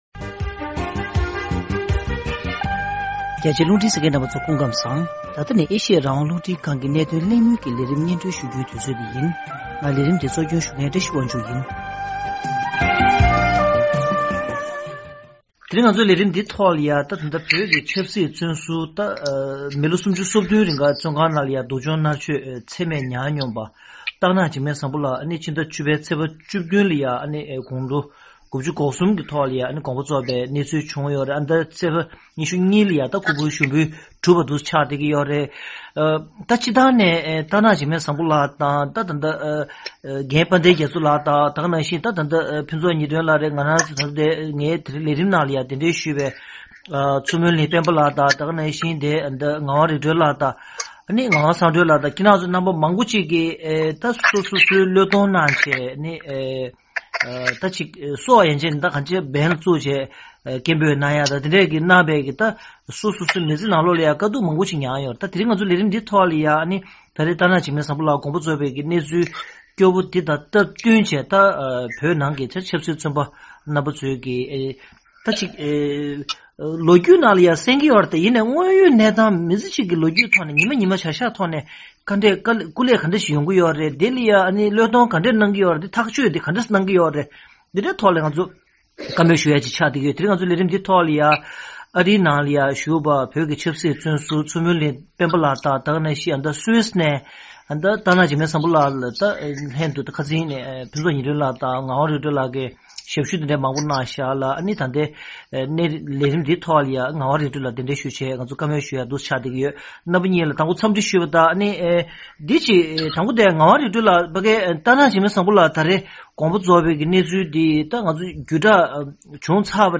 གནད་དོན་གླེང་མོལ་གྱི་ལས་རིམ་ནང་།